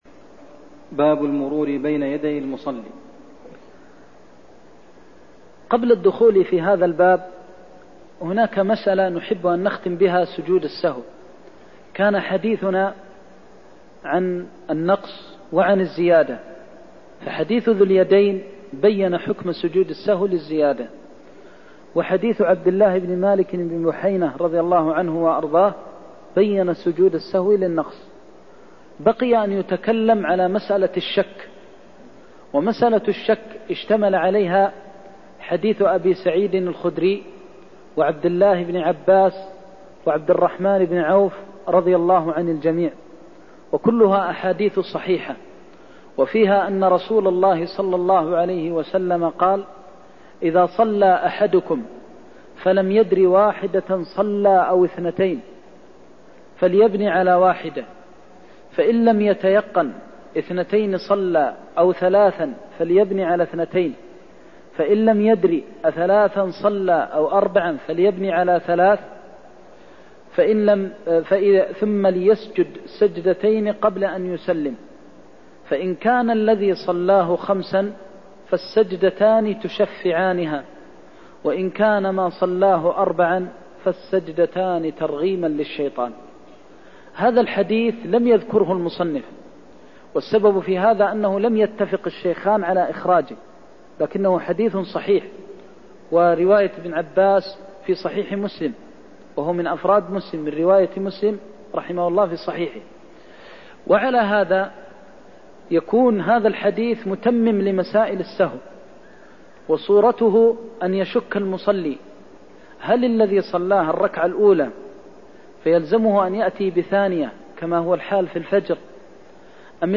المكان: المسجد النبوي الشيخ: فضيلة الشيخ د. محمد بن محمد المختار فضيلة الشيخ د. محمد بن محمد المختار الترهيب من المرور بين يدي المصلي (102)